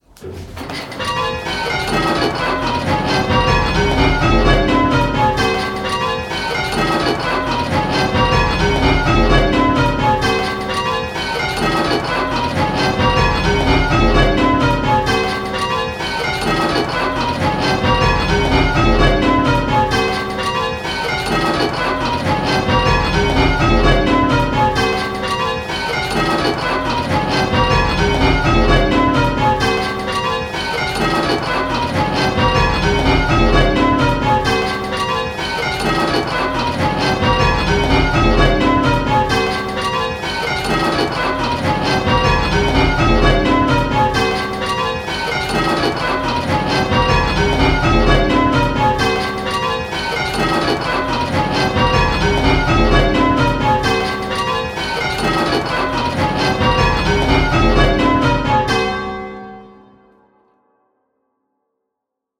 Listen to each clip and choose which bell is ringing early… the faults have been randomly placed, so there is no pattern and there might be consecutive clips that have the same fault!
Click the play button and listen to the rhythm of the 10 bells…. the rhythm will sound a bit lumpy!
Rounds-10-2E.m4a